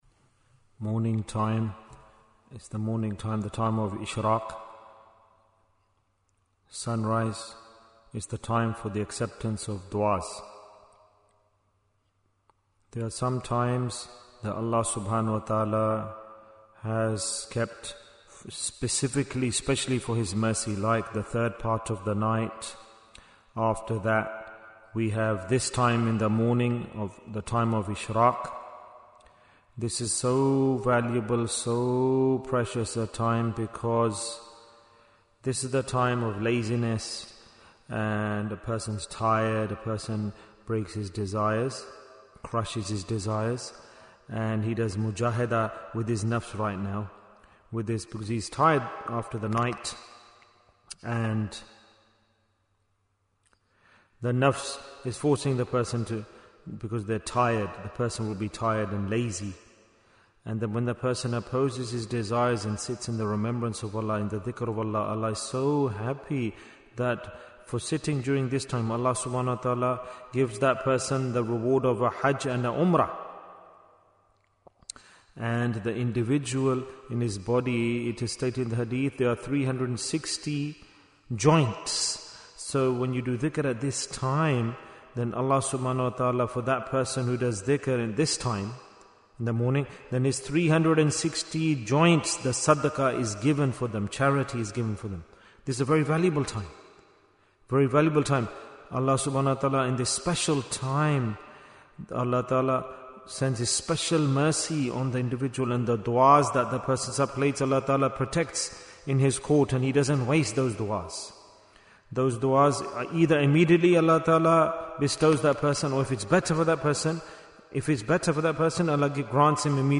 Talk before Dhikr 227 minutes16th August, 2025